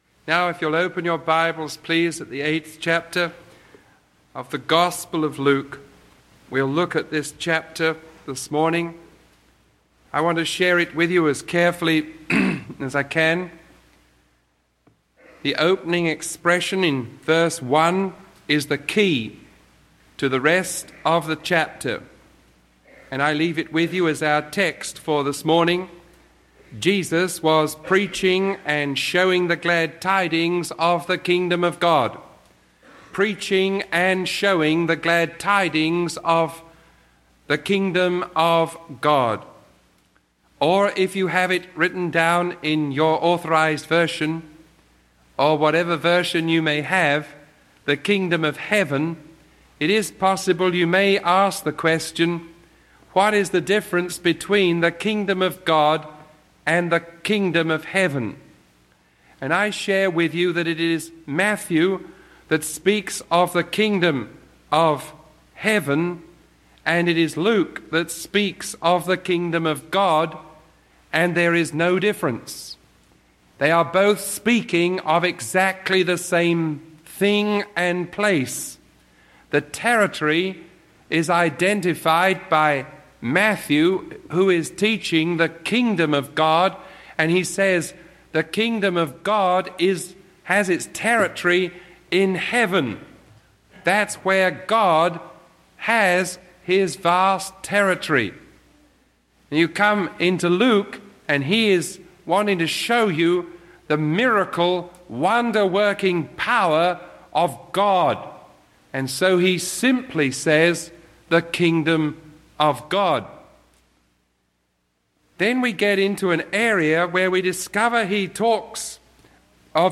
Sermon 0046B recorded on April 29